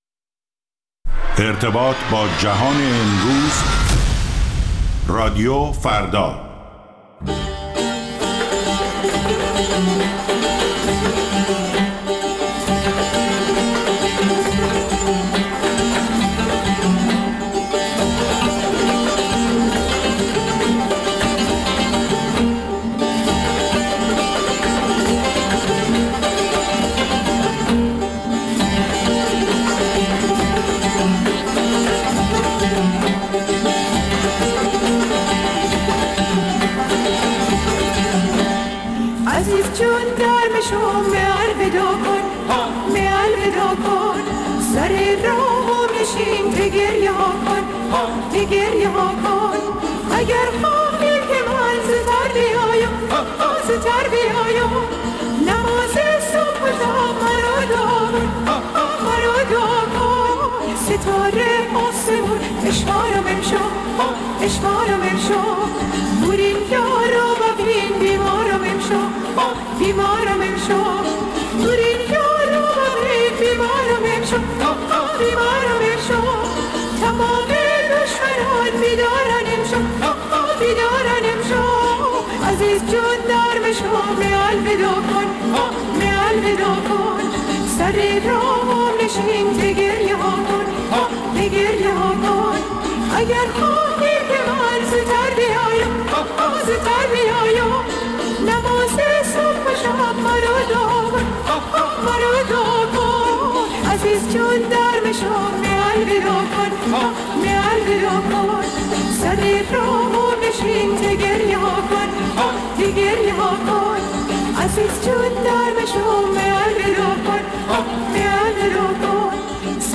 ویژه برنامه موسیقی محلی ایران